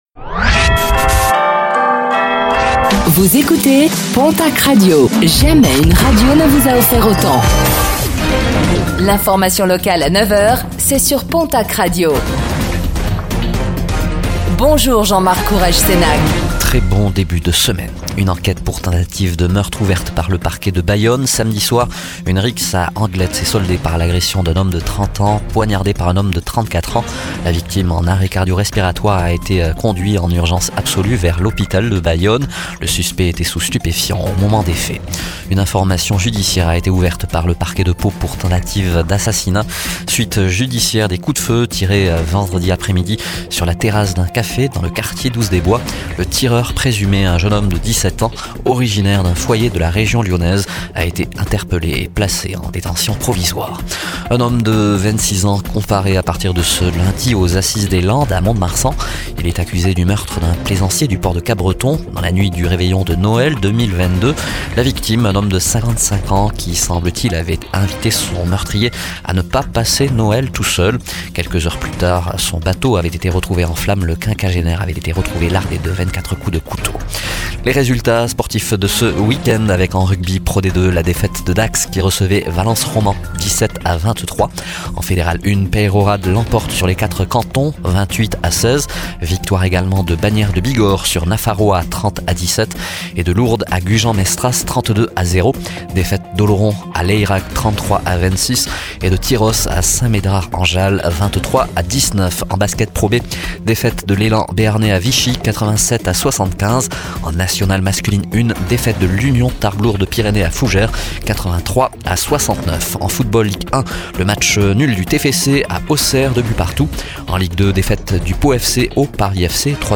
Réécoutez le flash d'information locale de ce lundi 10 février 2025